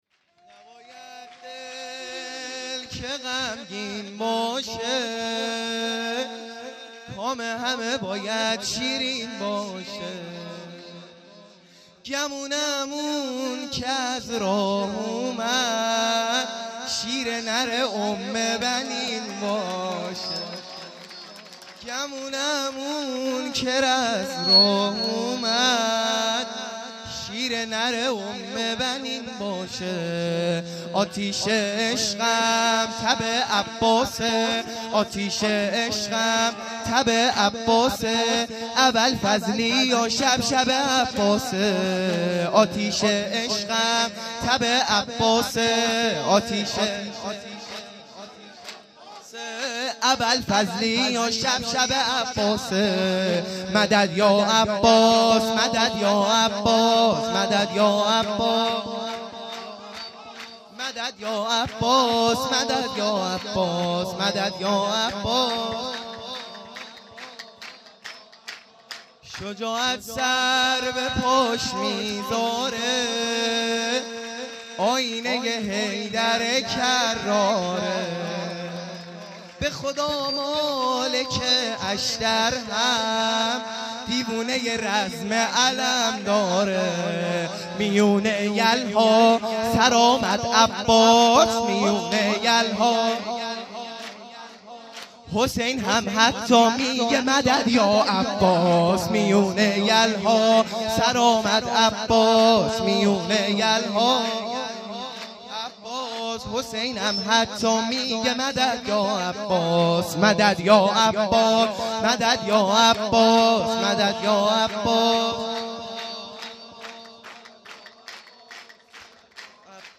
سرود | هر دل که غمگین باشه